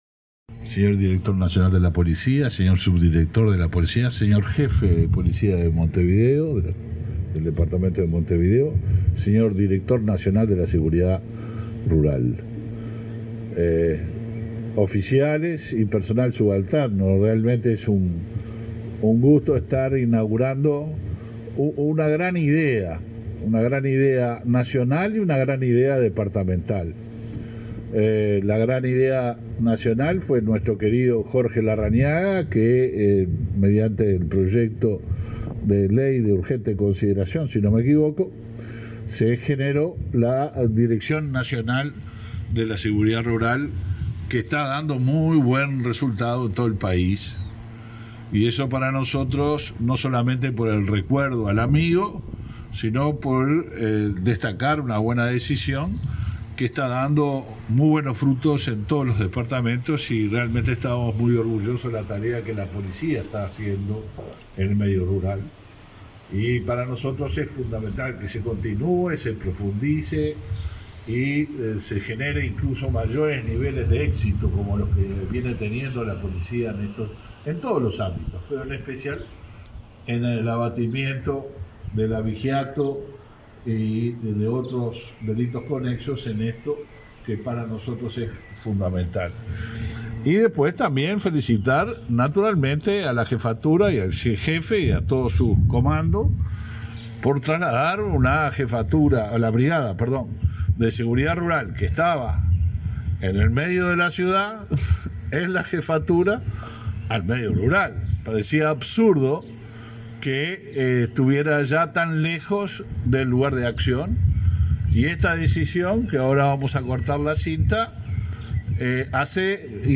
Conferencia de prensa de autoridades del Ministerio del Interior
Participaron en el evento el ministro Luis Alberto Heber y el jefe de Policía de Montevideo, Mario D' Elia.